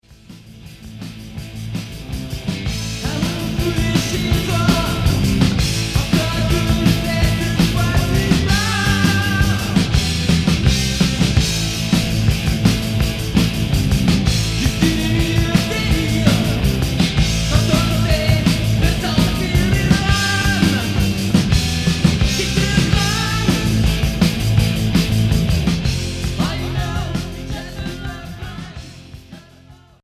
Hard